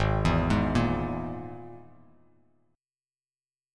G#sus2#5 chord